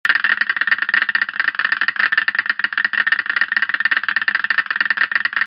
Клешни раков и крабов, бег краба и другие скачать в mp3 формате
2. Лапы краба по полу (эффект)